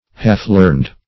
Half-learned \Half"-learned`\ (h[aum]f"l[~e]rnd`), a. Imperfectly learned.
half-learned.mp3